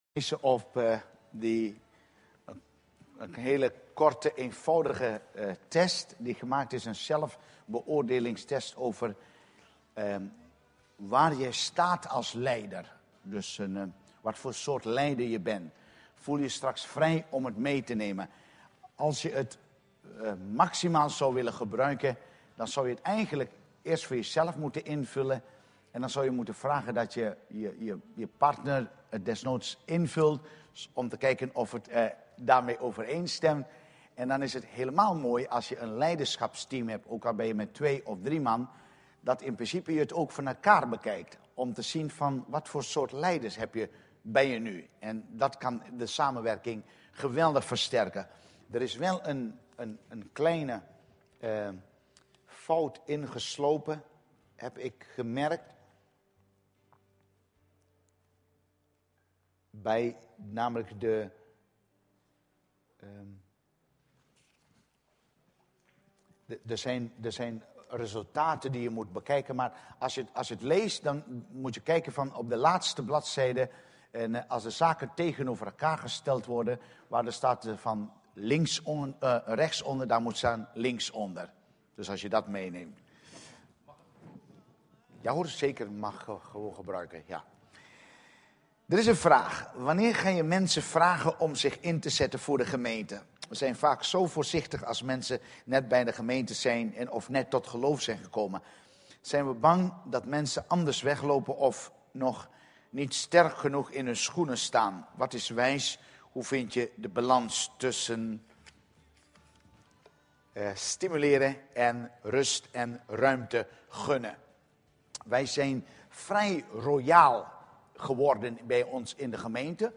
Dienstsoort: Studiedag